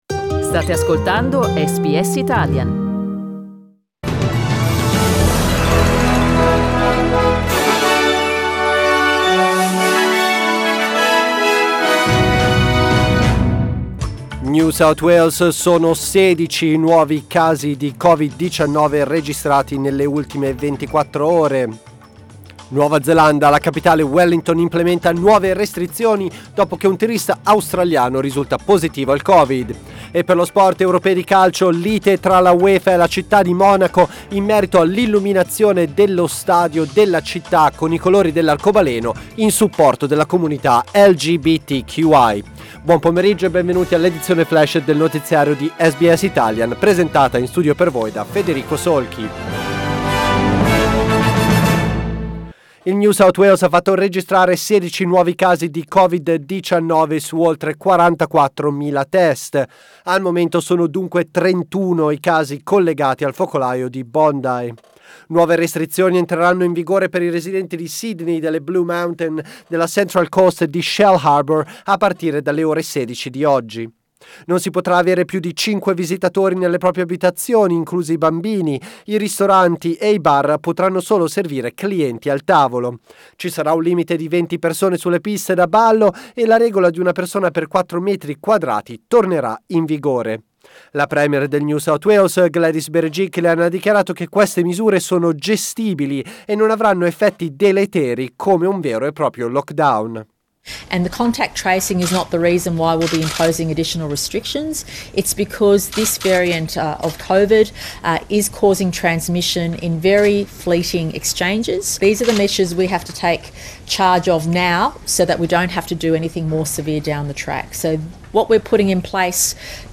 L'aggiornamento delle notizie di SBS Italian.